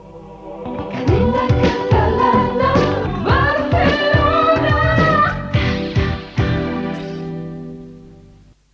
Indicatius de la cadena a Barcelona